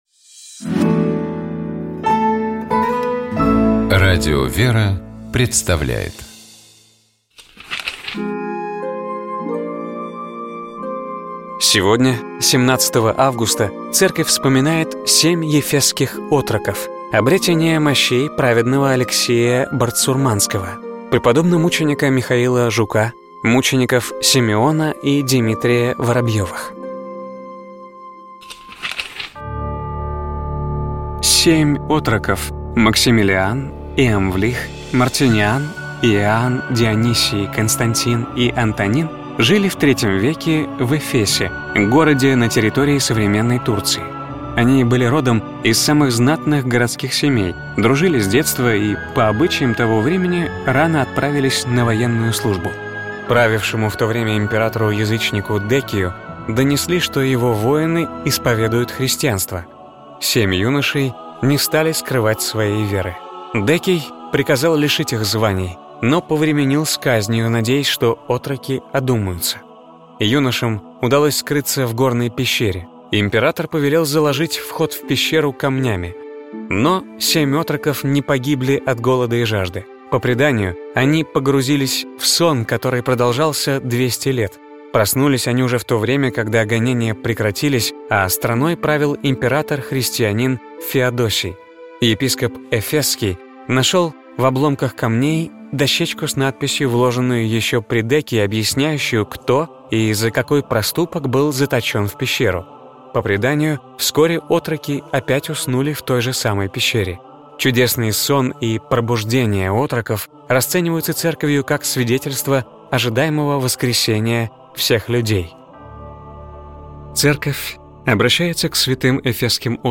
Поздравление Святейшего Патриарха Московского и всея Руси Кирилла с праздником Рождества Христова